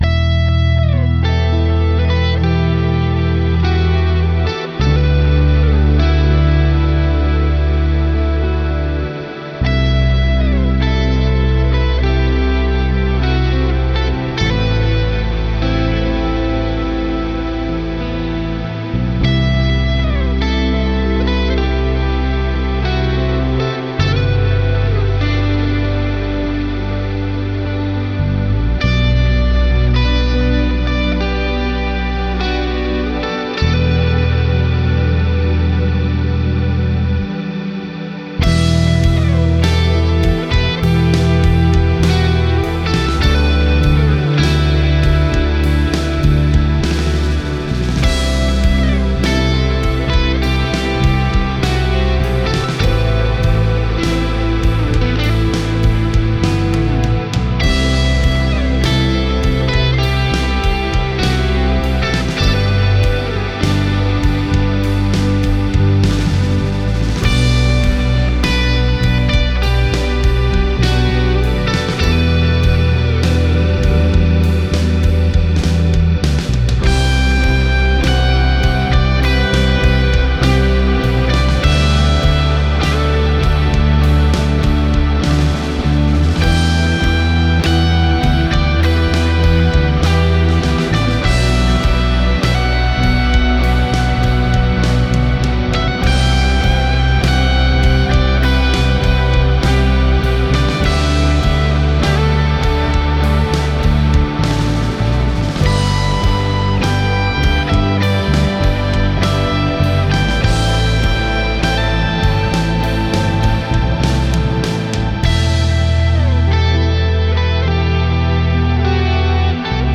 Genre Melodic